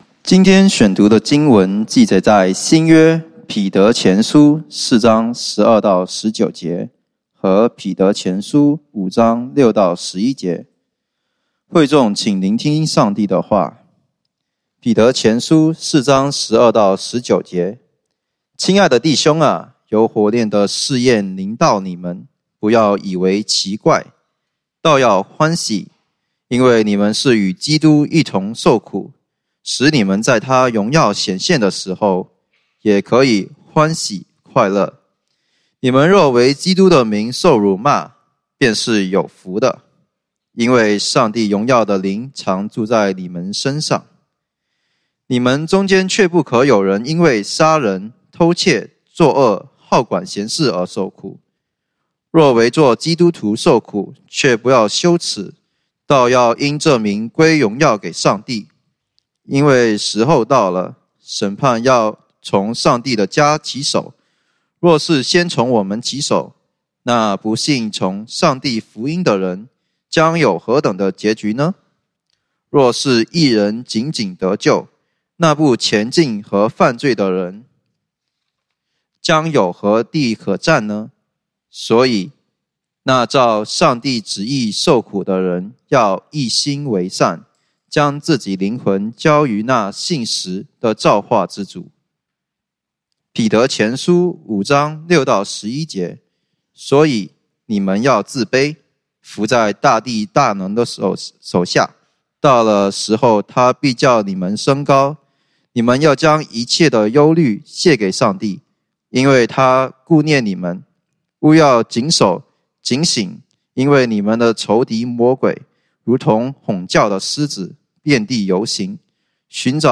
講道經文：彼得前書1 Peter 4:12-19; 5:6-11